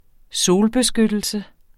Udtale [ ˈsoːl- ]